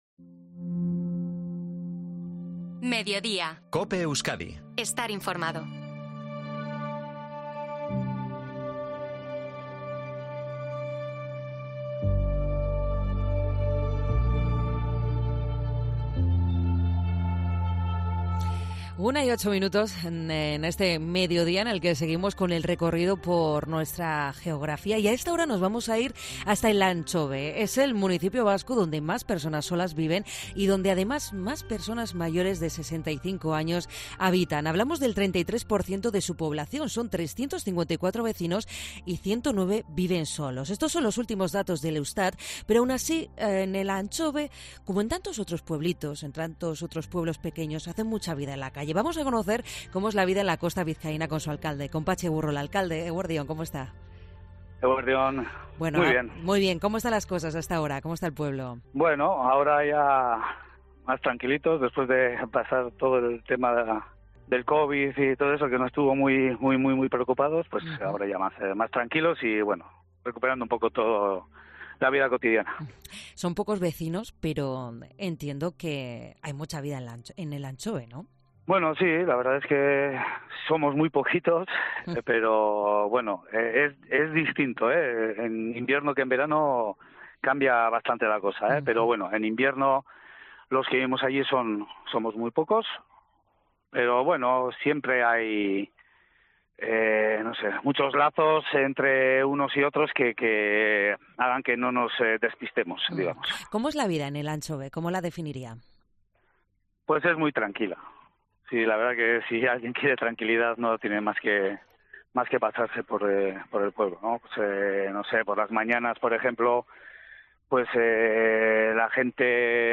Entrevista a Patxi Egurrola, alcalde de Elantxobe